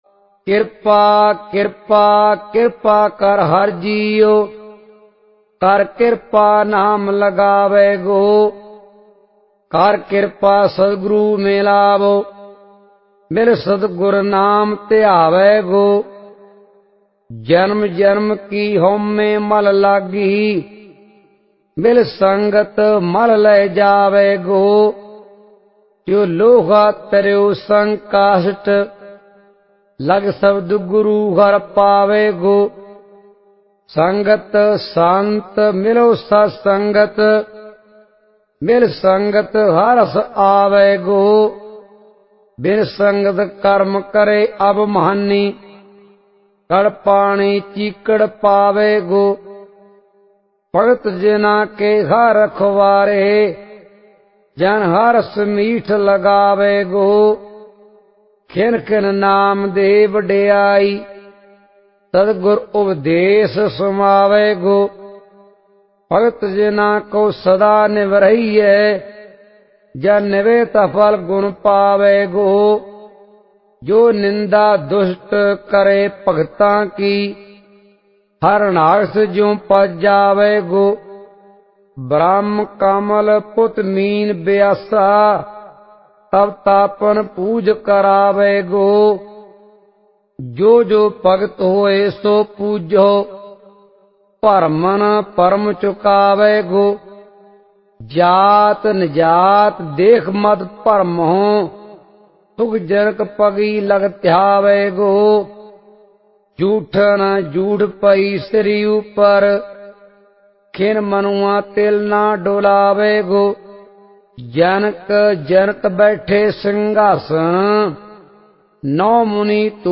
SGGS Sehaj Paath